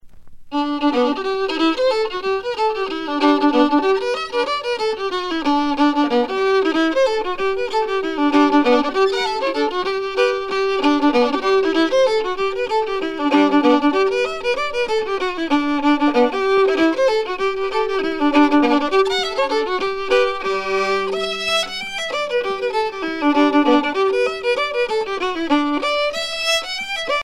danse : hornpipe
Pièce musicale éditée